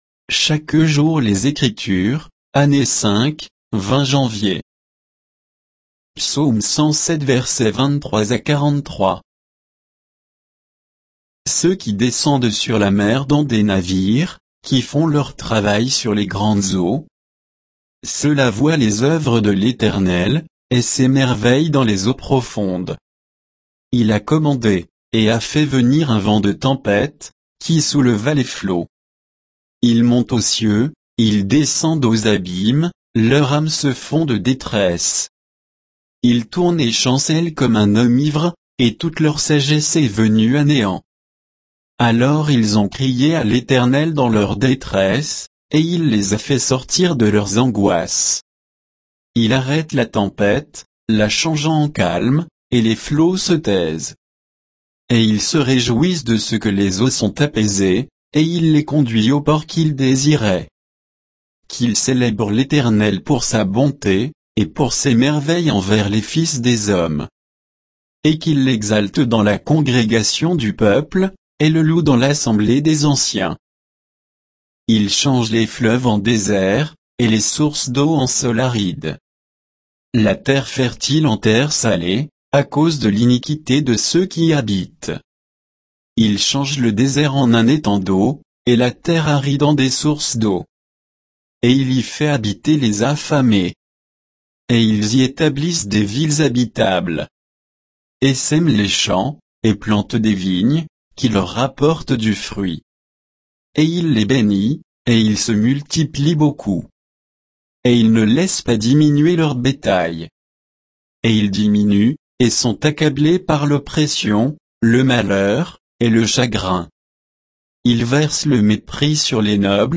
Méditation quoditienne de Chaque jour les Écritures sur Psaume 107